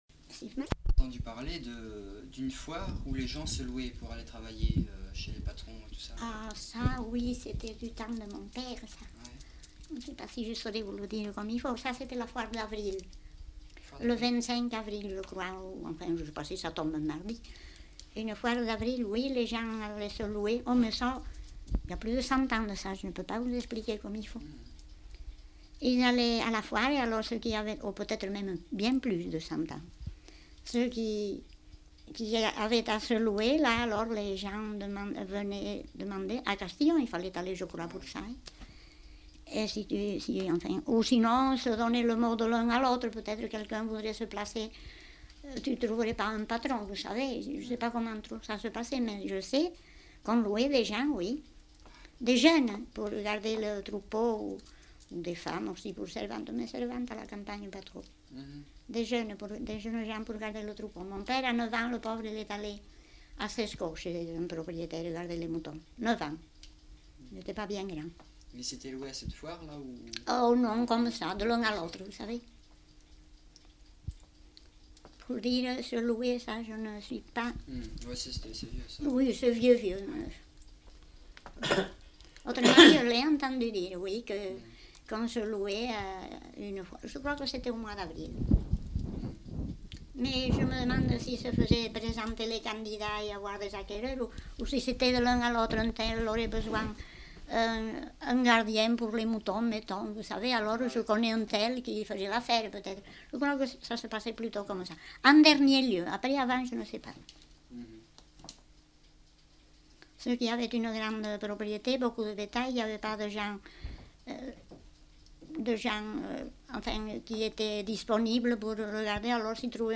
Gala folklorique à Castillon le 19 août
enquêtes sonores